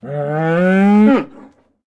Index of /HCU_SURVIVAL/Launcher/resourcepacks/HunterZ_G4/assets/minecraft/sounds/mob/cow
say4.ogg